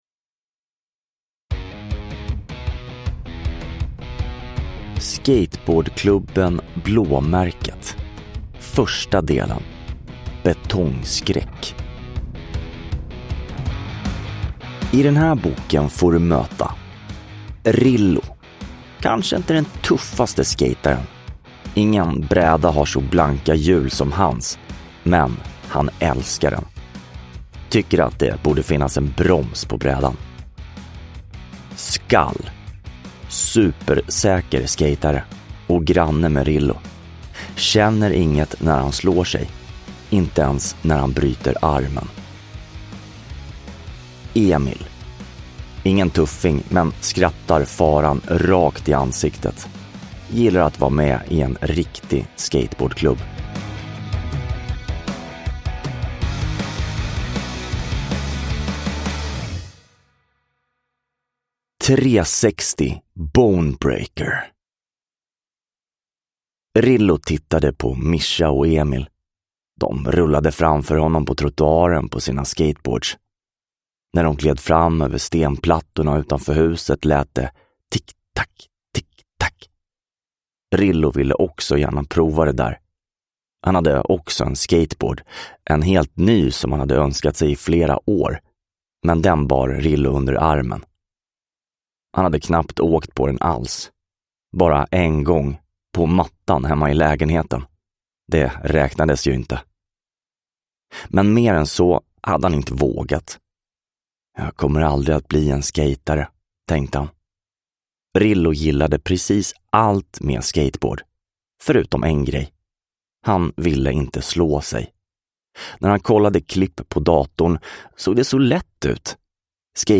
Betongskräck – Ljudbok
Uppläsare: